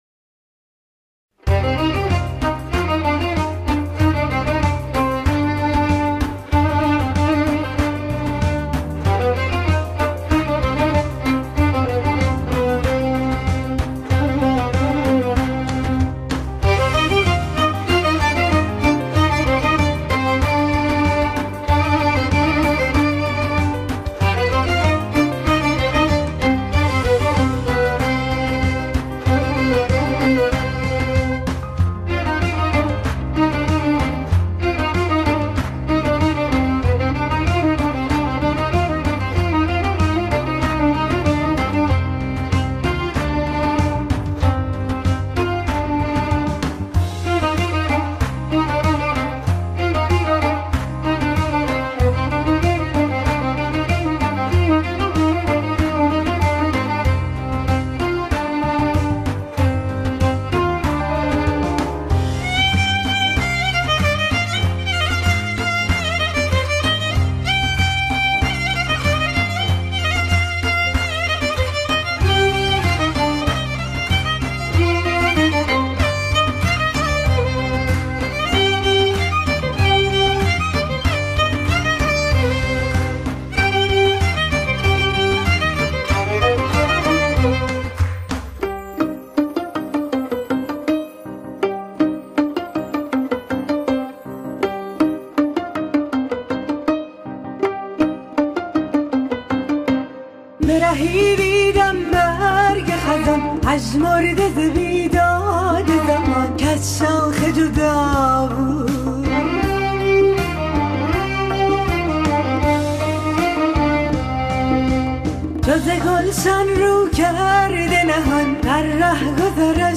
در دستگاه بیات اصفهان